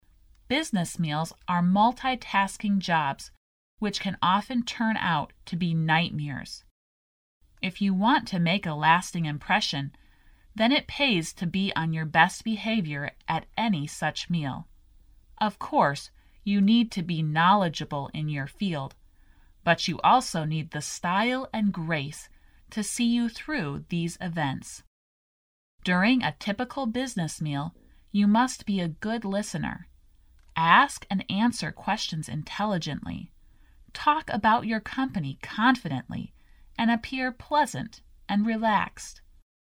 第一册英语单词朗读录音